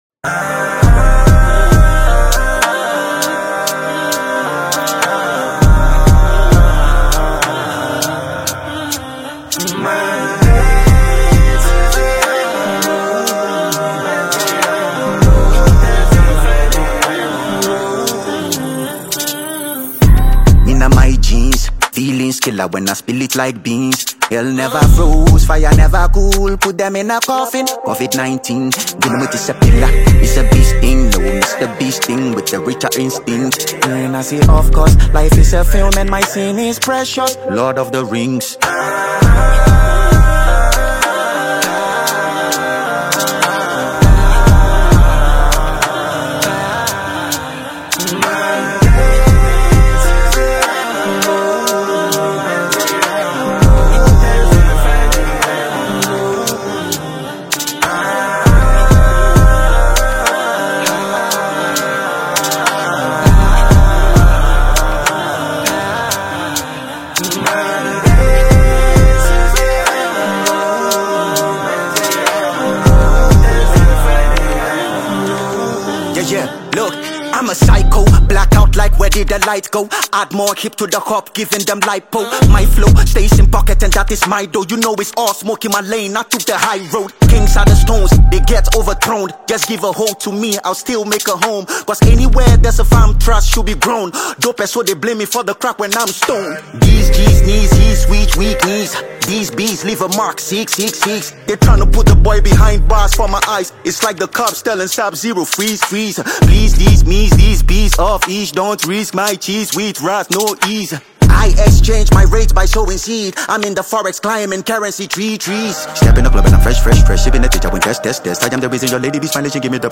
is an energetic dancehall track
• Genre: Dancehall / Reggae